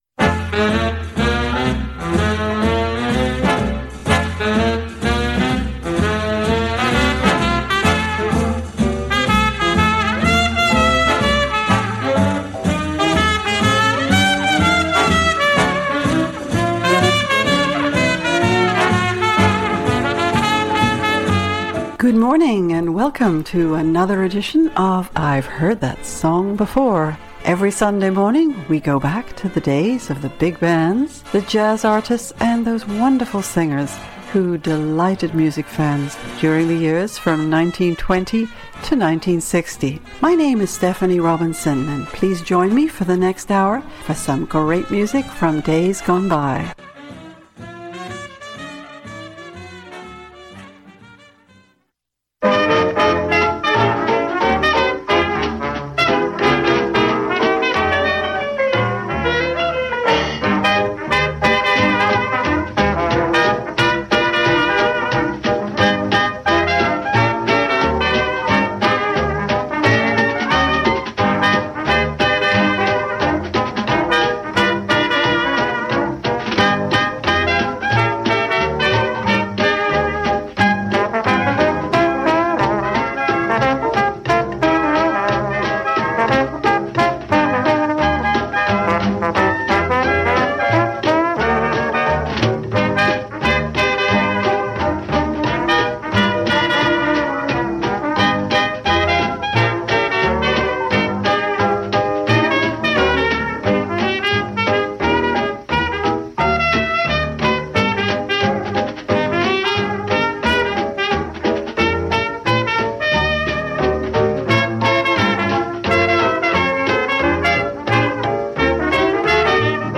small jazz groups